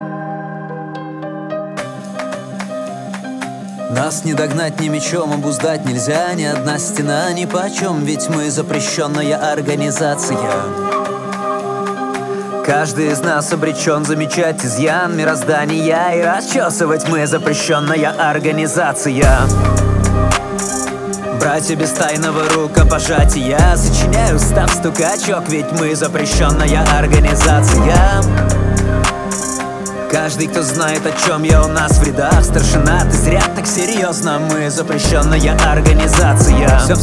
• Качество: 320, Stereo
мужской голос
русский рэп
спокойные
пианино